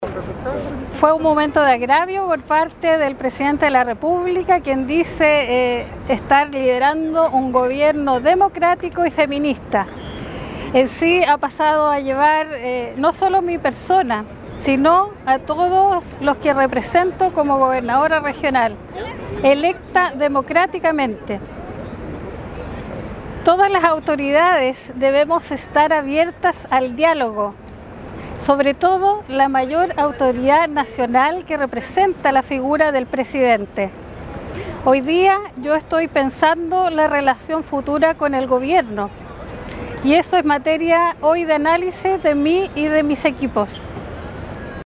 Así lo relató Naranjo.